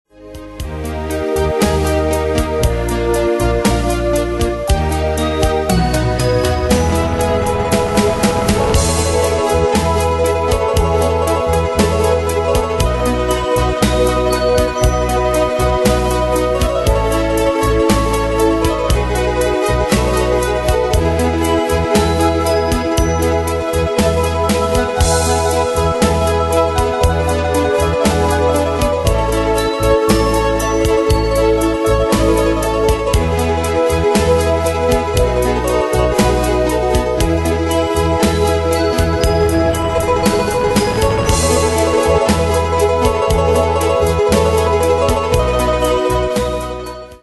Demos Midi Audio
Danse/Dance: Ballade Cat Id.
Pro Backing Tracks